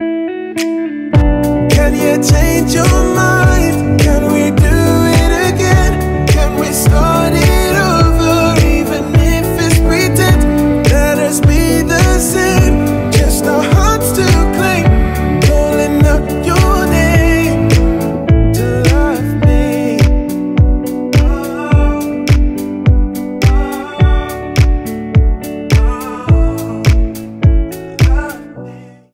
• Качество: 320 kbps, Stereo
Ремикс